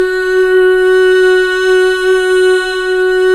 Index of /90_sSampleCDs/Club-50 - Foundations Roland/VOX_xFemale Ooz/VOX_xFm Ooz 1 S